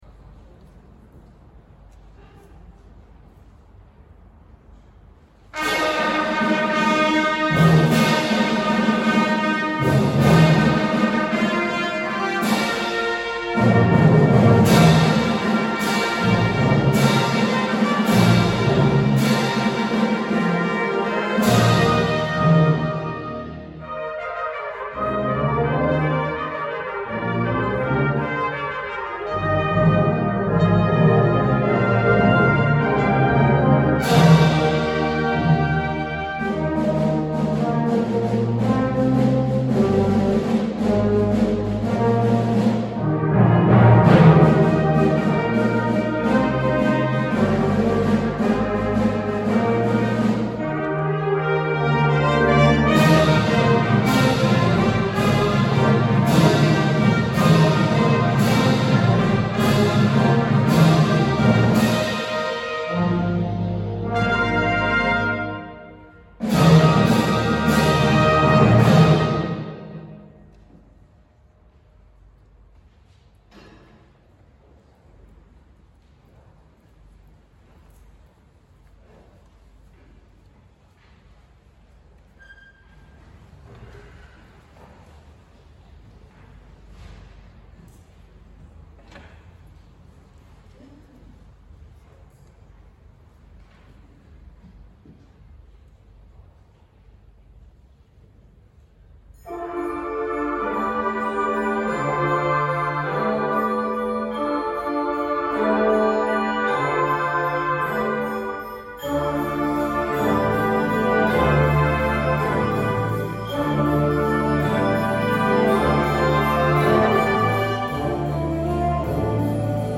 Secondary Carol Service 2021